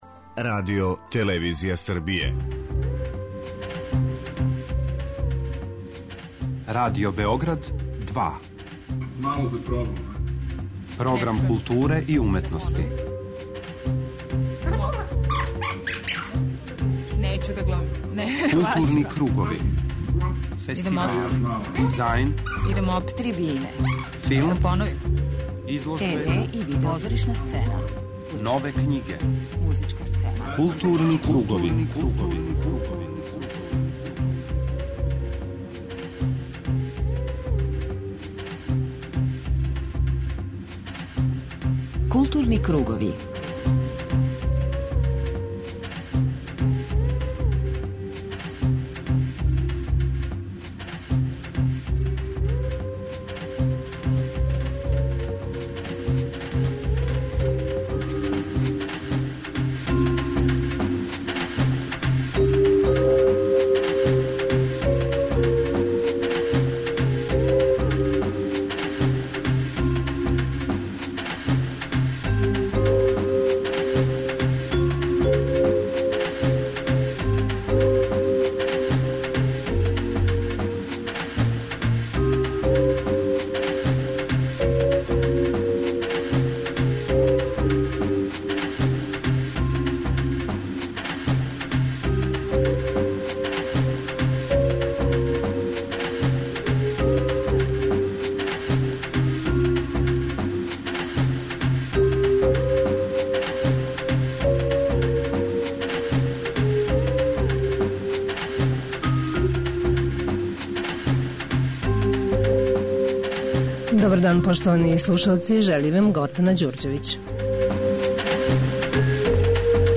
преузми : 41.17 MB Културни кругови Autor: Група аутора Централна културно-уметничка емисија Радио Београда 2.